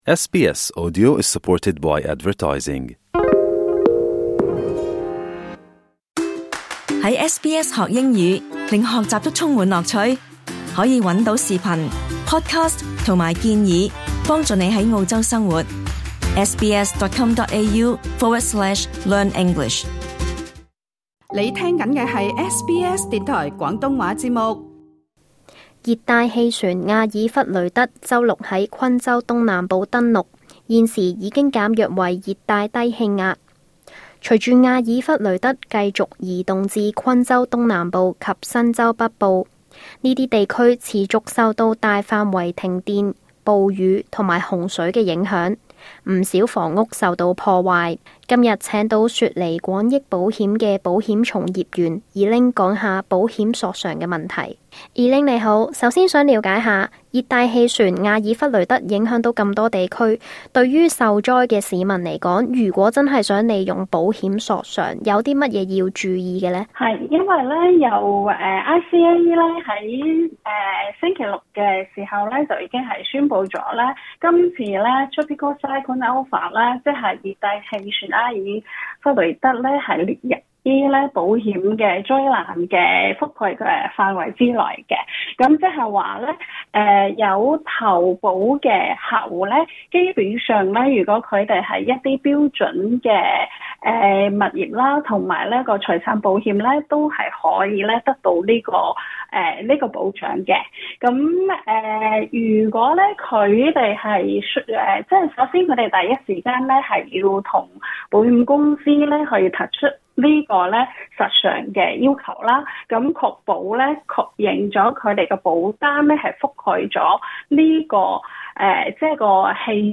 想了解更多關於與自然災害相關保險索償的問題，請留意足本錄音訪問。